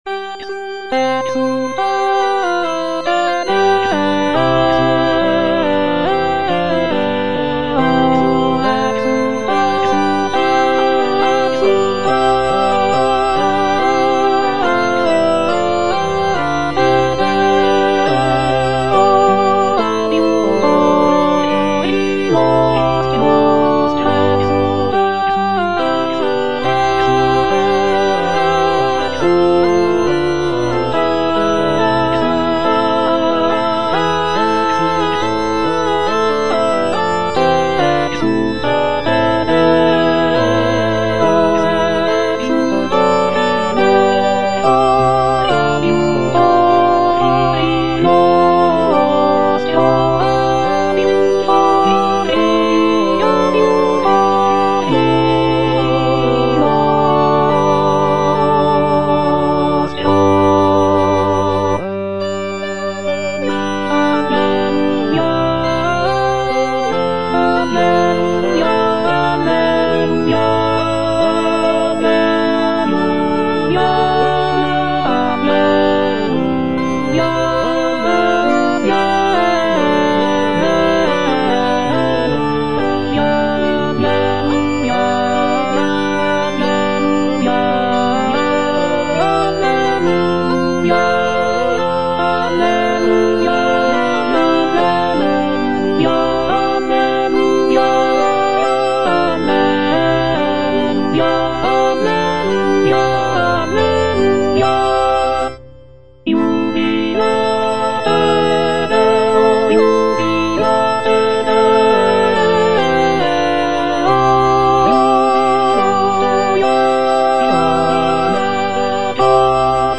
A.SCARLATTI - EXULTATE DEO (EDITION 2) Alto (Emphasised voice and other voices) Ads stop: auto-stop
"Exultate Deo (edition 2)" by A. Scarlatti is a sacred choral work that showcases the composer's mastery of the Baroque style. The piece is known for its uplifting and jubilant tone, with intricate counterpoint and rich harmonies.